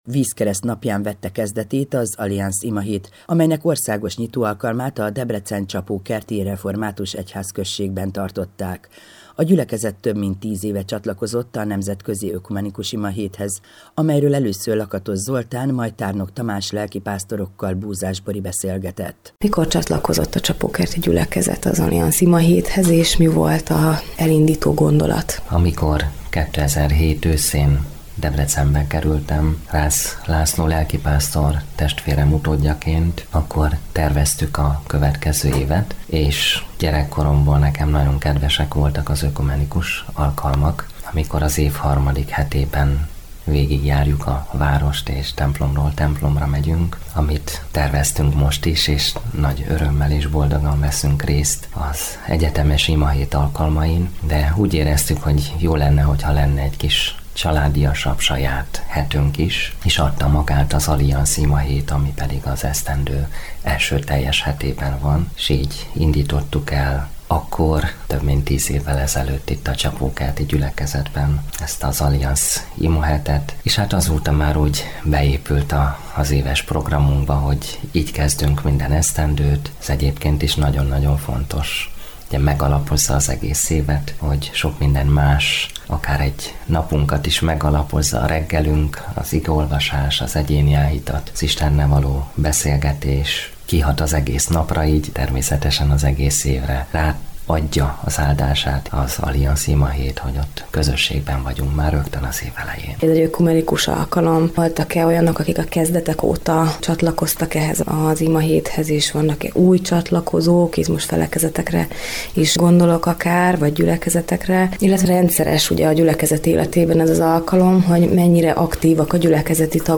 interjút